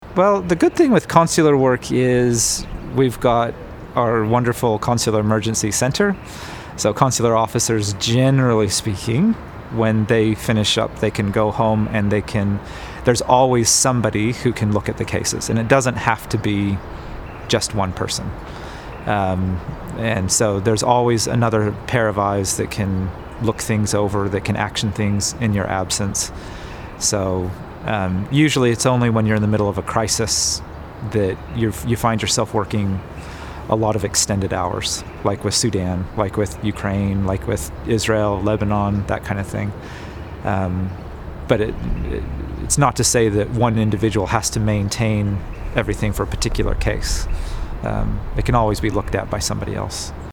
a consular officer